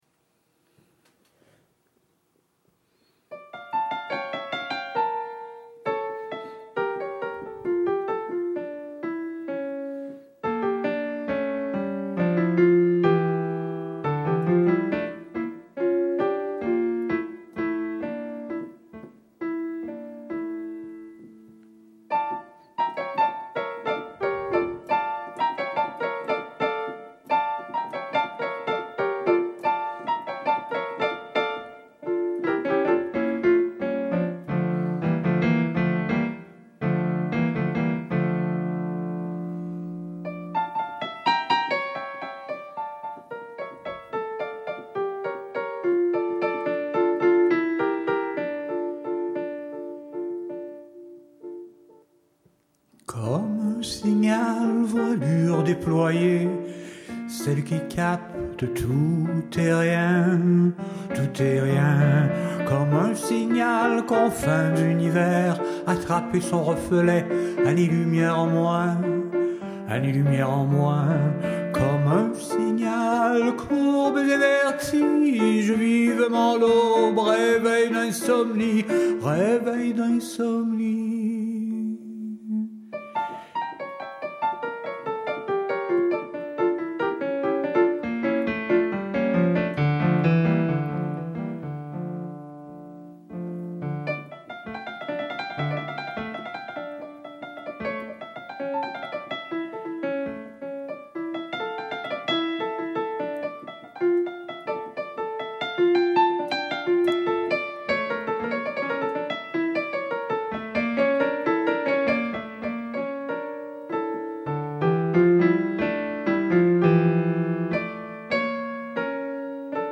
Version de 2014 Piano et Voix